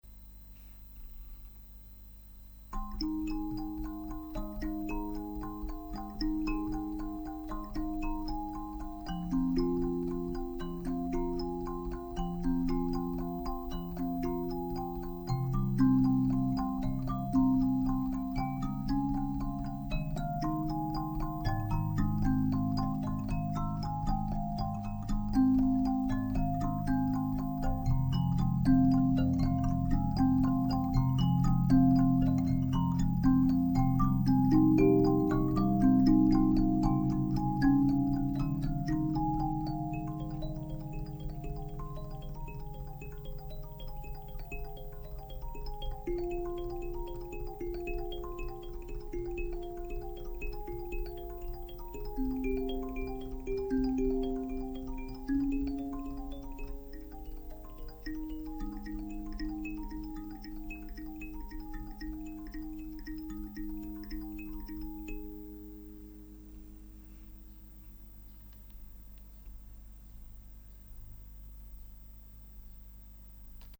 半音階カリンバその３（POLON）
音域はF2からF6までの４オクターブです。
左手で伴奏をしていると、どうしても低音が欲しくなって来たのでこのようになりました。
これを完全になくす事は出来ませんが、出来るだけ目立たなくなるように振動体と箱の構造を工夫しました。